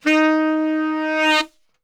D#2 SAXSWL.wav